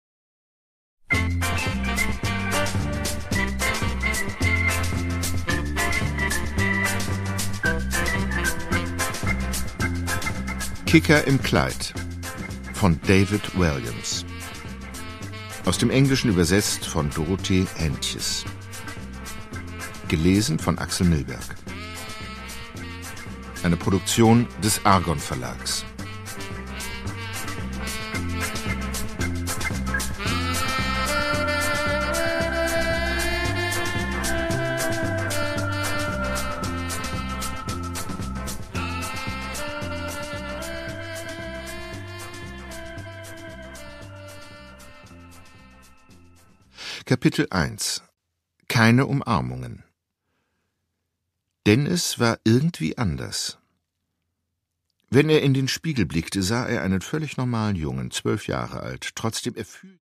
Produkttyp: Hörbuch-Download
Gelesen von: Axel Milberg